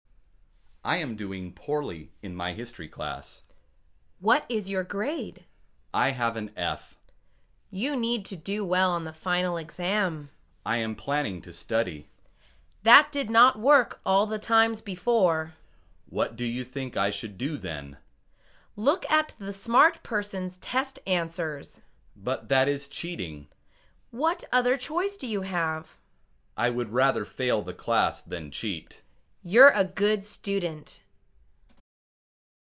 مجموعه مکالمات ساده و آسان انگلیسی – درس شماره هشتم از فصل زندگی محصلی: انجام کار درست